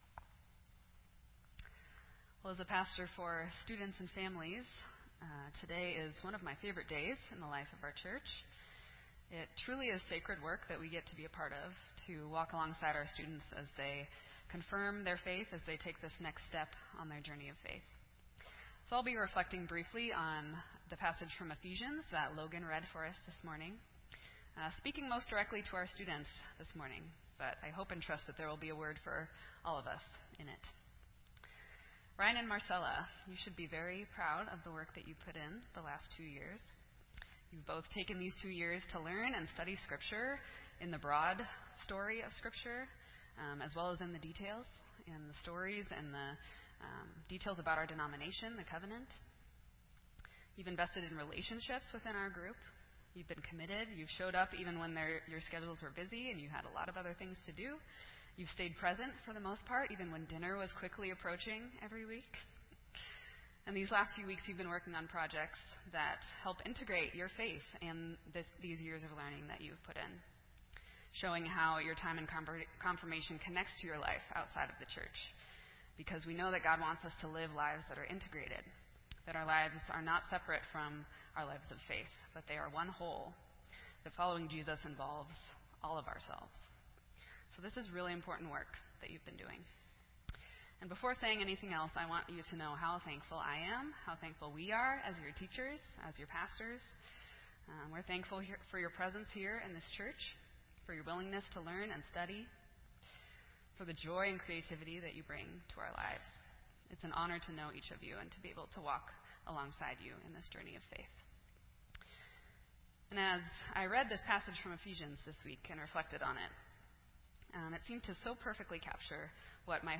(Confirmation Sunday)
This entry was posted in Sermon Audio on May 14